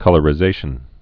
(kŭlər-ĭ-zāshən)